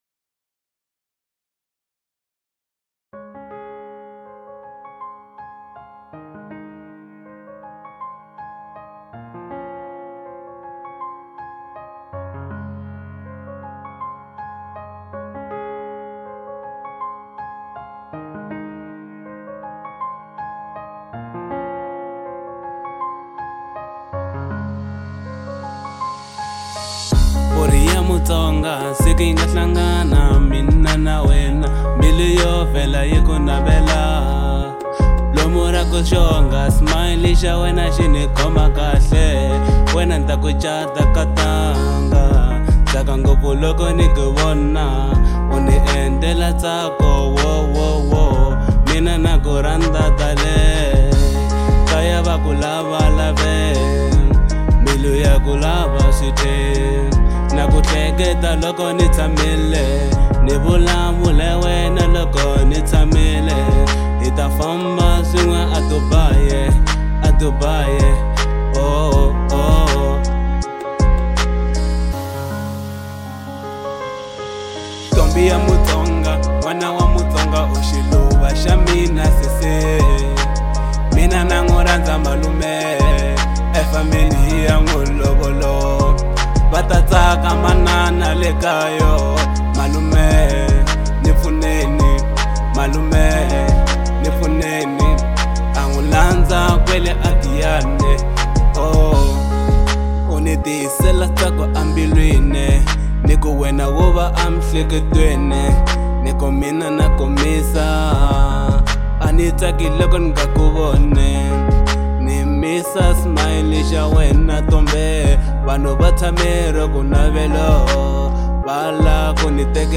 03:32 Genre : Hip Hop Size